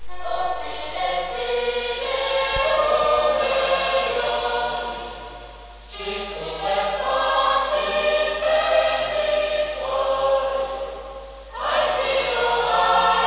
wav file of D'Walser (per sentire il canto corale) (to hear the song)